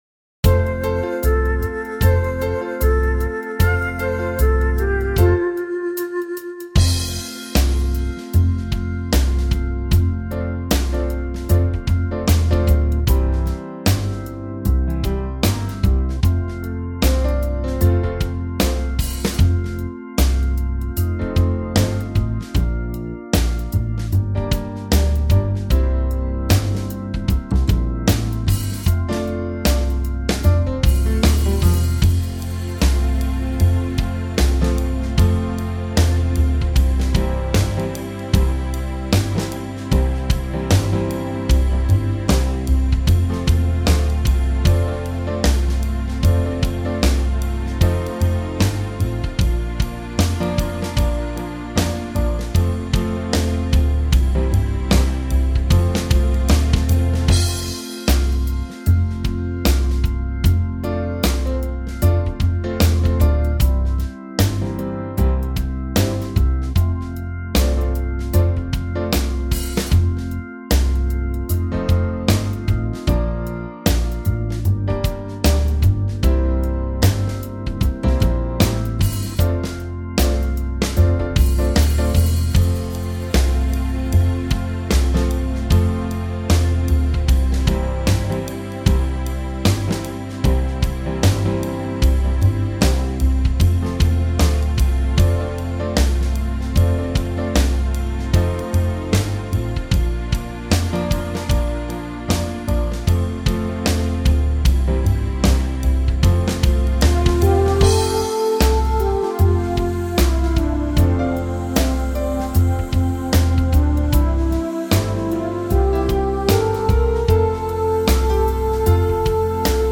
Swing